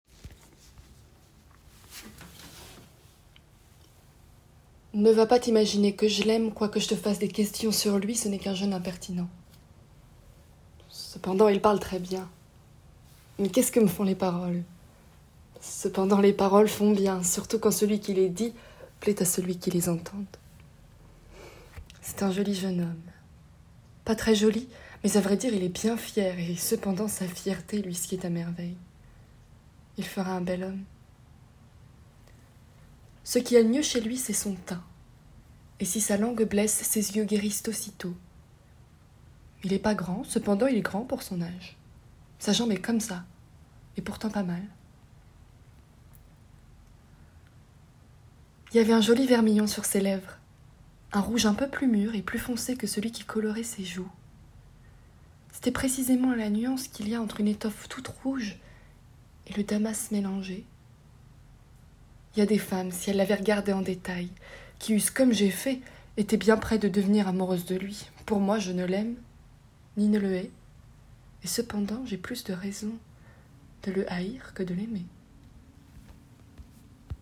PHOEBE SHAKESPEARE MONOLOGUE